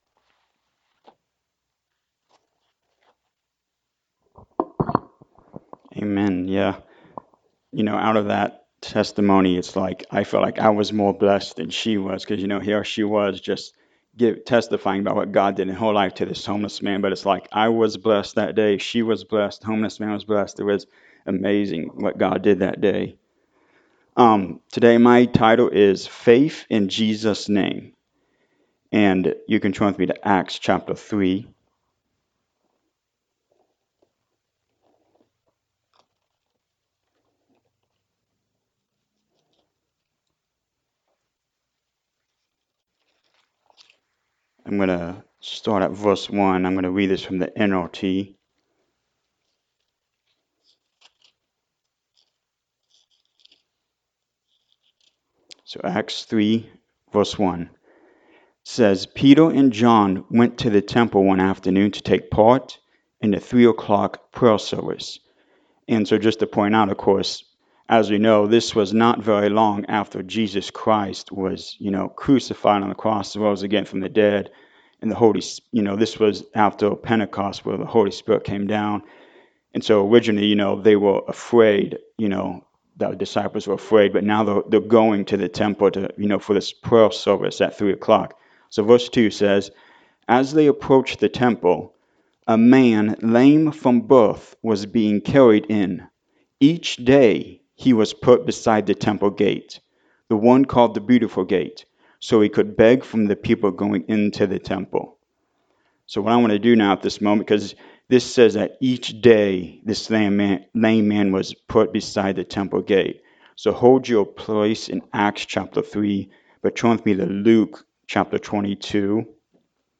Acts 3:1-26 Service Type: Sunday Morning Service We all want miracles in our lives.